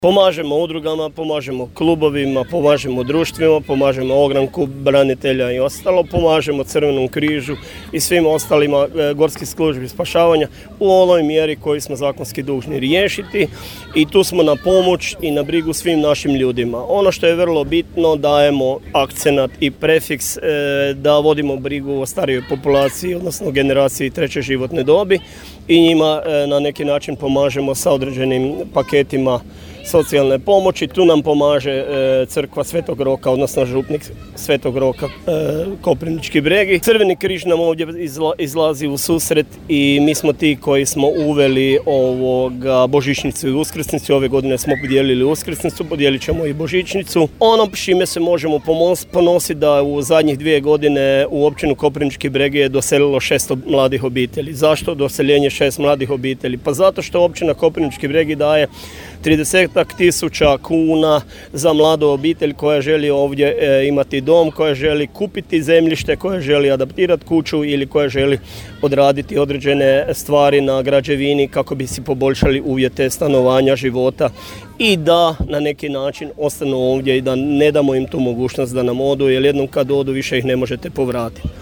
Načelnik Općine Koprivnički Bregi Mato Kuzminski iskazao je ponos te naveo kako je Općina na raspolaganju za pomoć udrugama;